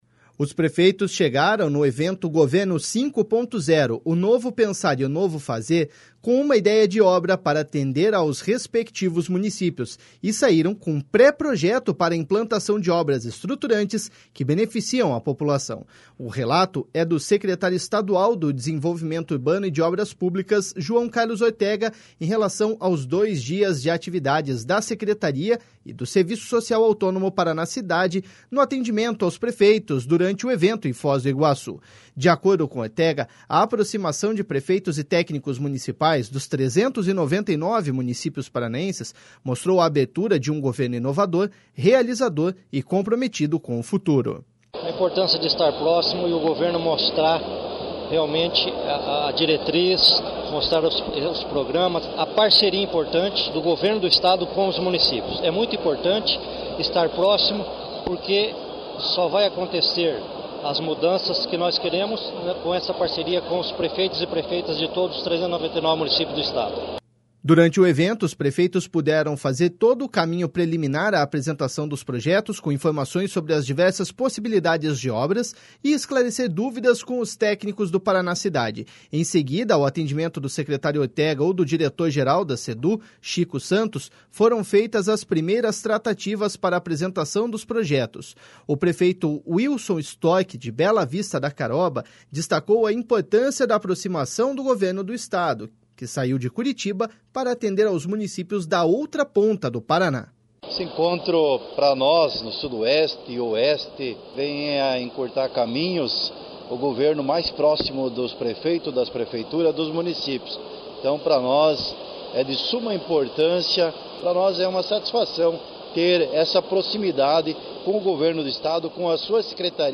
De acordo com Ortega, a aproximação de prefeitos e técnicos municipais, dos 399 municípios paranaenses, mostrou a abertura de um governo inovador, realizador e comprometido com o futuro.// SONORA JOÃO CARLOS ORTEGA.//
O prefeito Wilson Storch, de Bela Vista da Caroba, destacou a importância da aproximação do Governo do Estado, que saiu de Curitiba para atender aos municípios da outra ponta do Paraná.// SONORA WILSON STORCH.//
José Salim Haggi Neto, prefeito de Cambará, disse que a orientação e a possibilidade de tirar dúvidas facilita o trabalho para ampliar o desenvolvimento no município em parceria com o governo do Estado.// SONORA JOSÉ SALIM.//